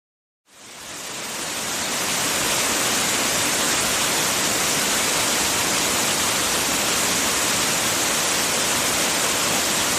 Whale Song
Whale Song is a free animals sound effect available for download in MP3 format.
487_whale_song.mp3